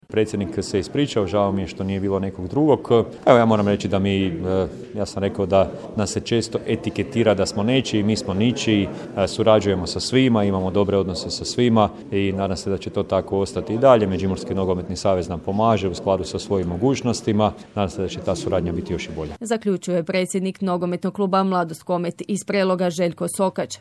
NK Mladost Komet, redovna godišnja skupština kluba, 10.2.2023. / Poduzetnički centar Prelog